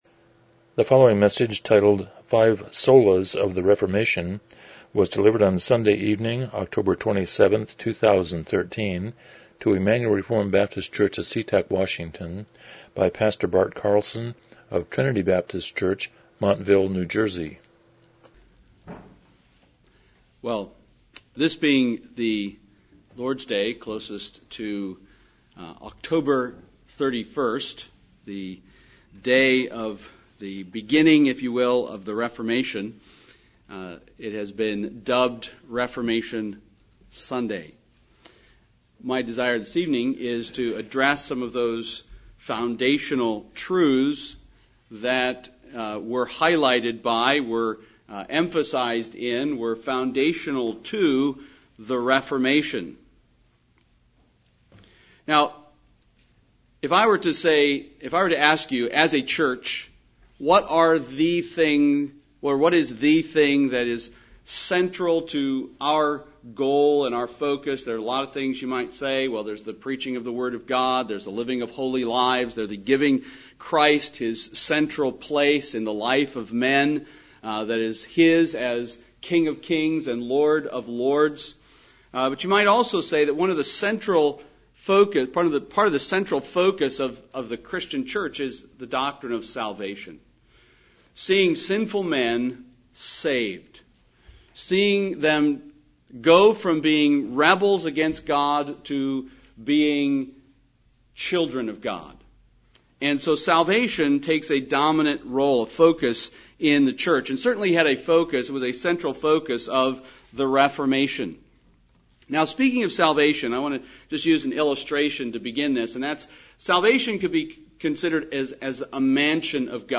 Miscellaneous Service Type: Evening Worship « Spiritual Social Netowrking 42 The Sermon on the Mount